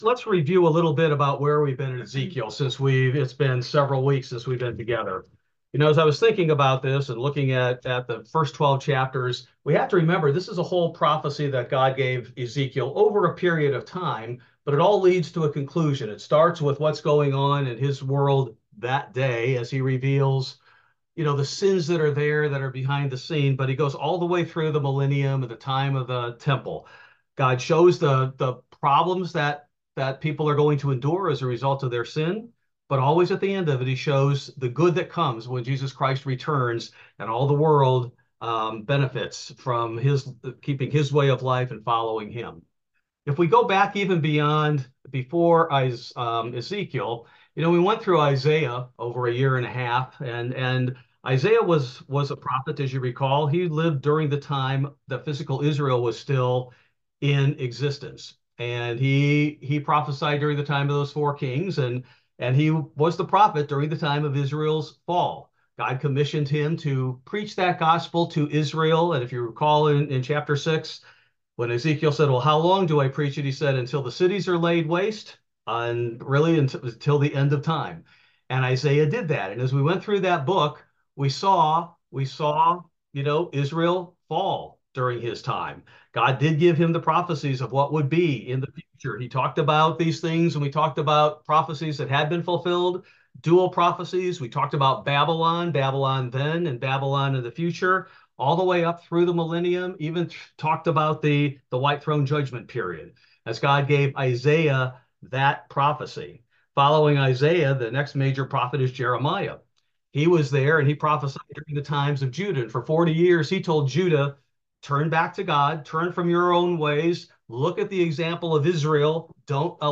Bible Study: August 7, 2024